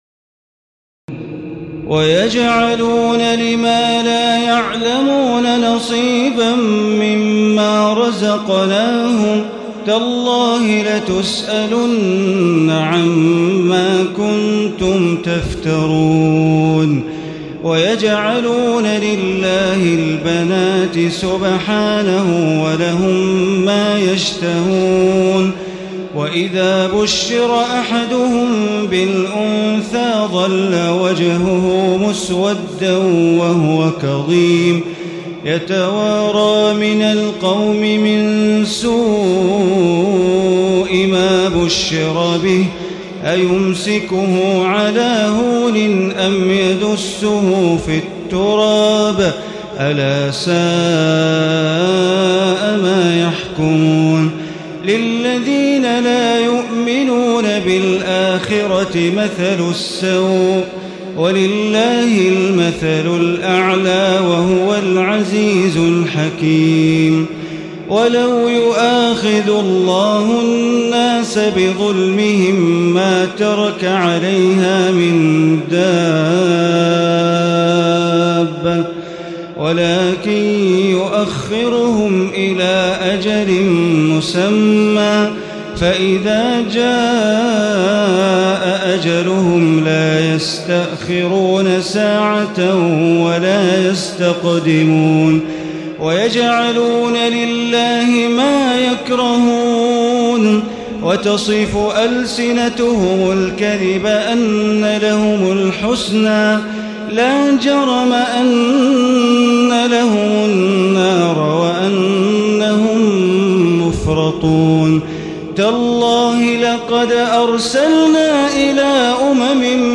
تراويح الليلة الثالثة عشر رمضان 1436هـ من سورة النحل (56-128) Taraweeh 13 st night Ramadan 1436H from Surah An-Nahl > تراويح الحرم المكي عام 1436 🕋 > التراويح - تلاوات الحرمين